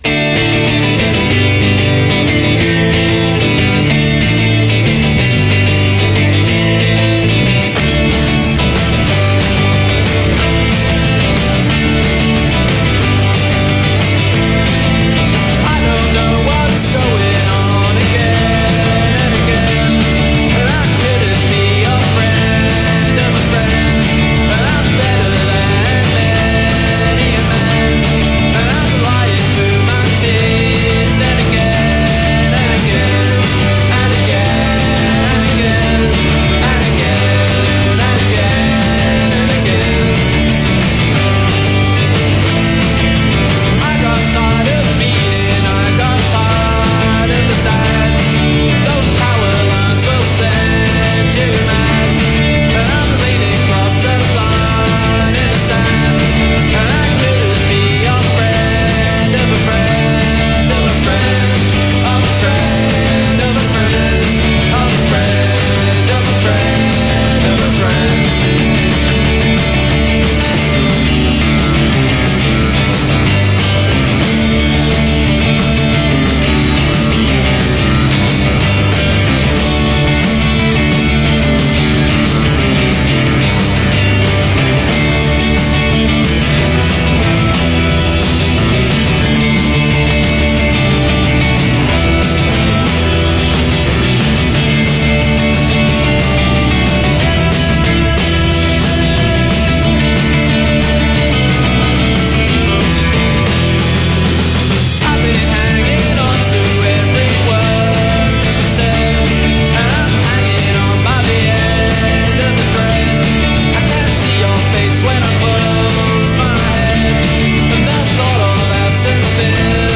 recorded live
Loose, clattering and a whiff of jangle thrown in.
Taking inspiration from vintage garage rock, surf
clattering, playful indie tunes